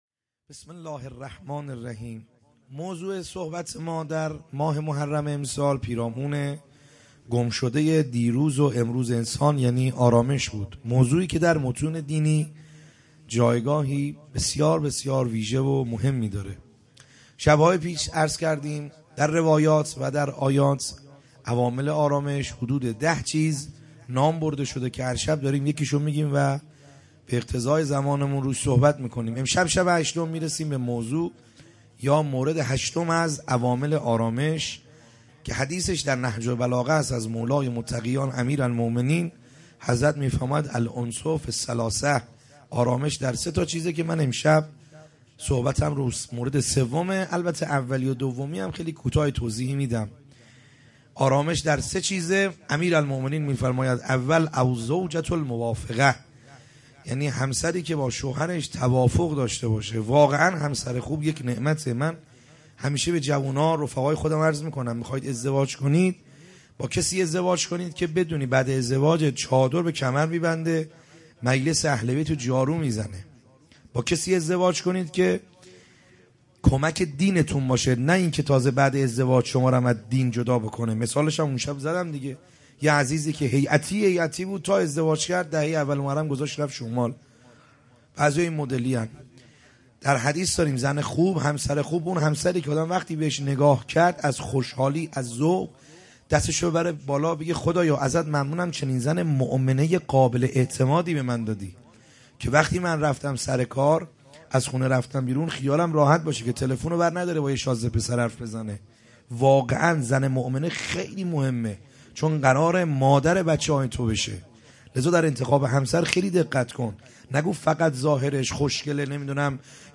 خیمه گاه - بیرق معظم محبین حضرت صاحب الزمان(عج) - سخنرانی